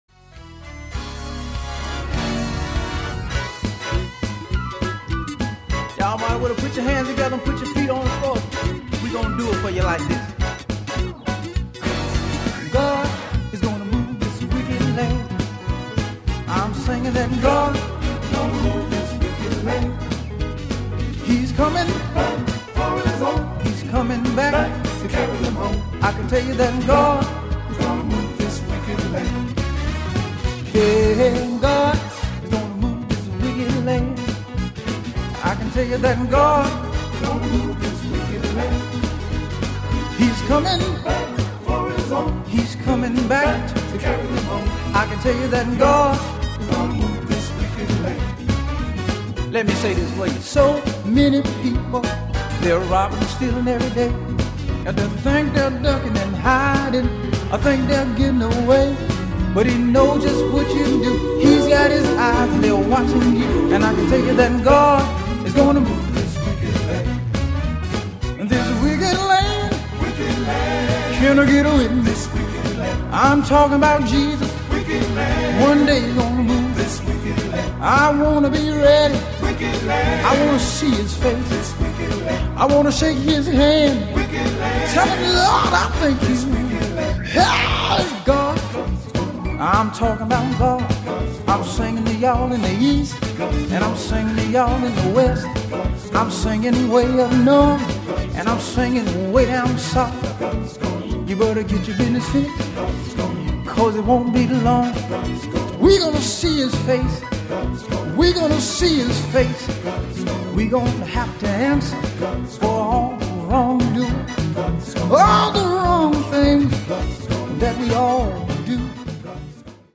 drums
bass guitar
guitars
piano Organ & Keyboards